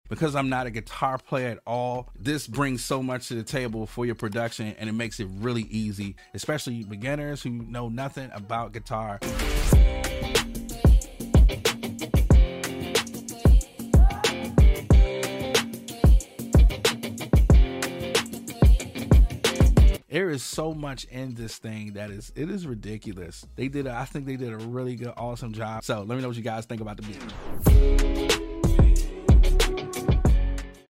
Craft guitar melodies in seconds with Virtual Guitarist SPARKLE 2!